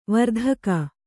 ♪ vardhaka